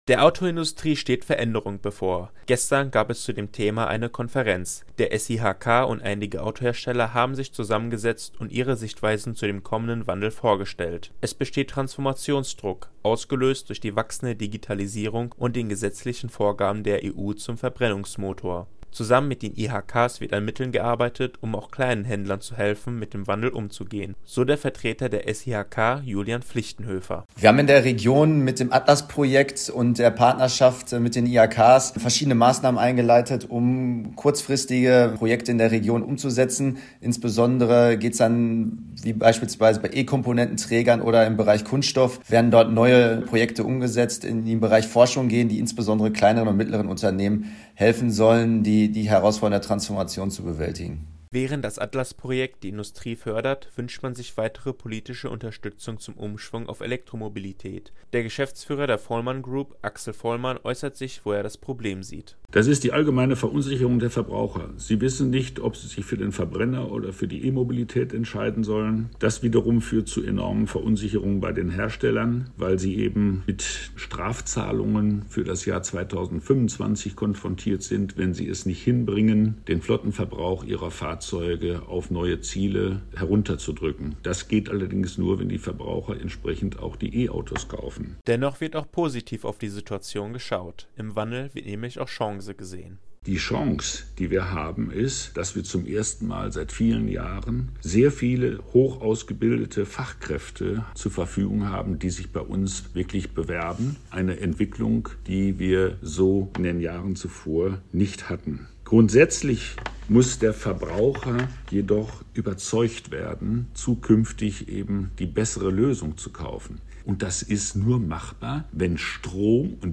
konferenz-zur-autoindustrie.mp3